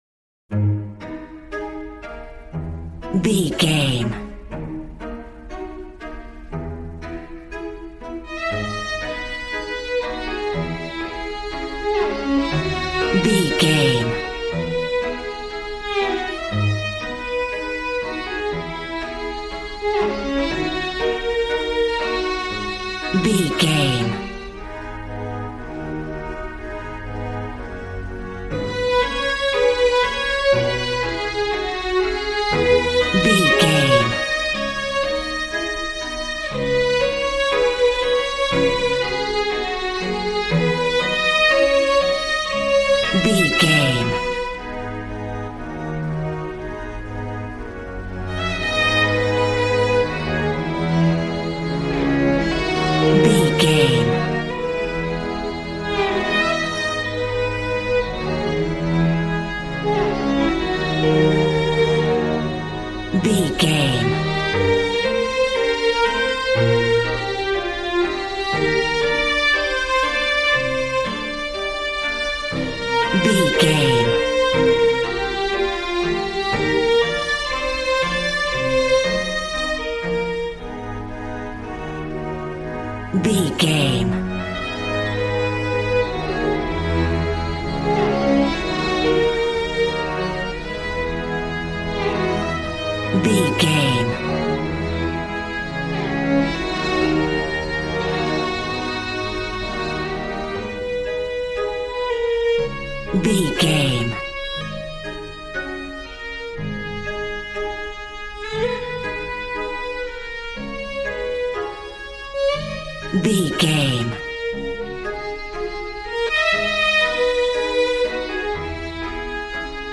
Aeolian/Minor
A♭
regal
piano
violin
strings